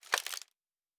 Plastic Foley 01.wav